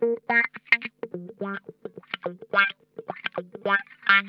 Index of /90_sSampleCDs/Zero G - Funk Guitar/Partition B/VOLUME 021